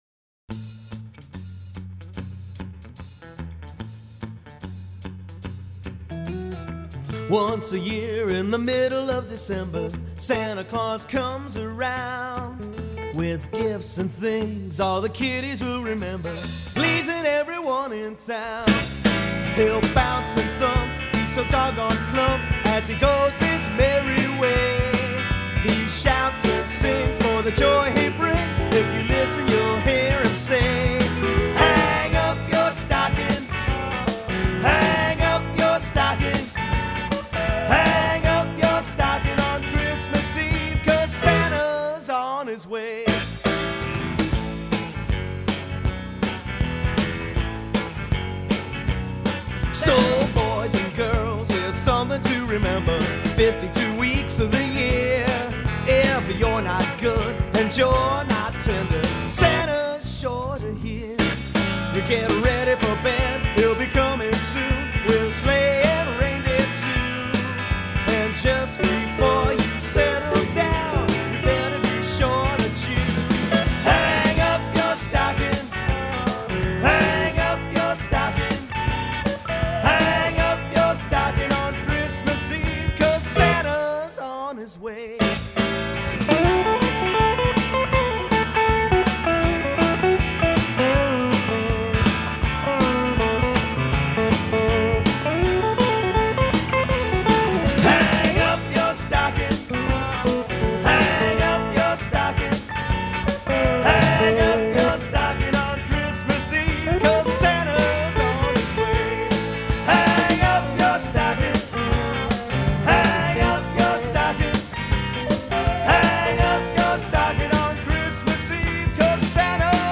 It features 18 songs by local Newark, Delaware musicians.